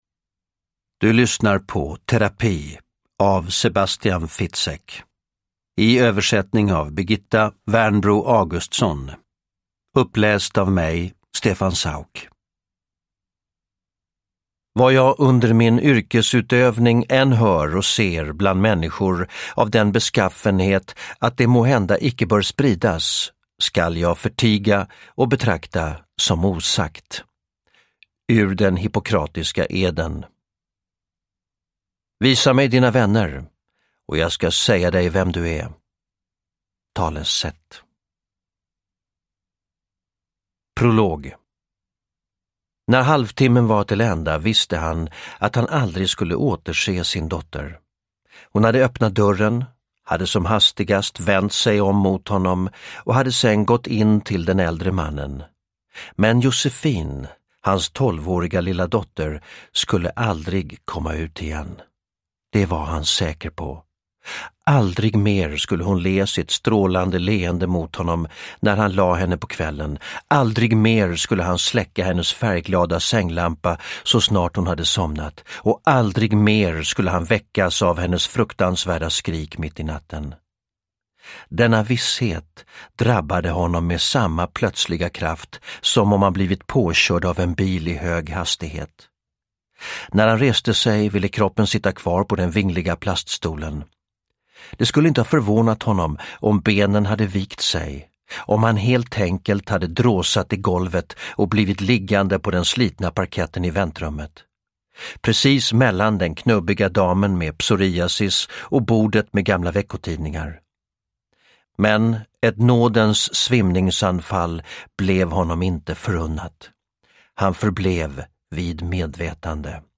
Terapi – Ljudbok – Laddas ner
Uppläsare: Stefan Sauk